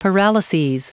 Prononciation du mot paralyses en anglais (fichier audio)
Prononciation du mot : paralyses